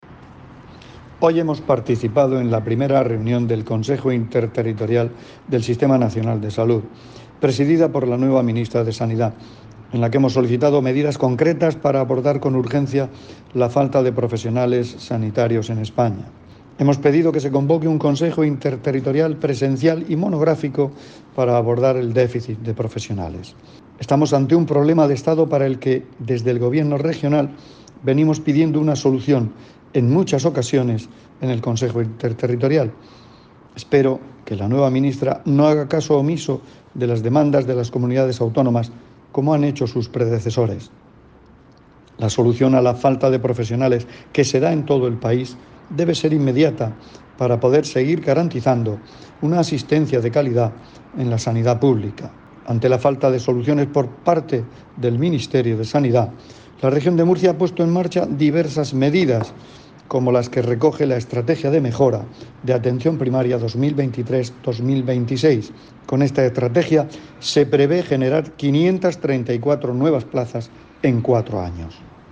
Declaraciones del consejero de Salud, Juan José Pedreño, sobre la reunión del Consejo Interterritorial del Sistema Nacional de Salud.